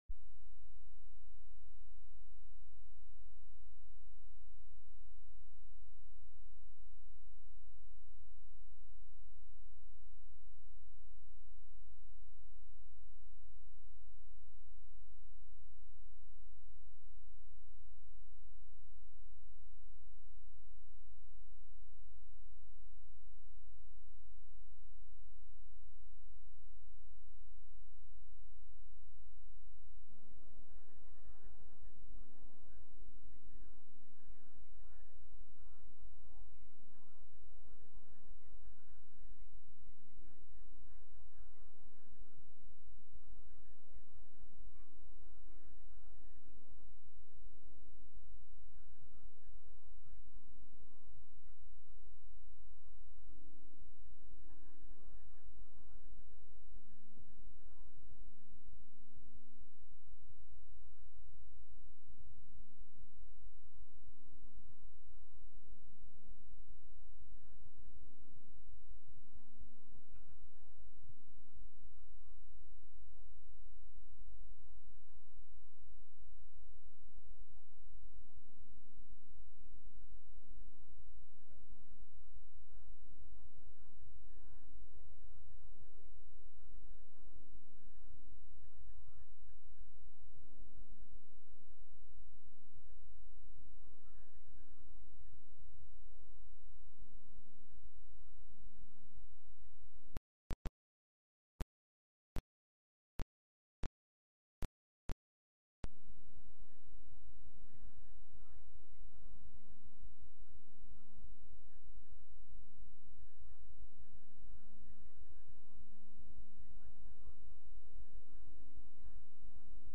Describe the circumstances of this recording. Sunday-Service-6-19-22.mp3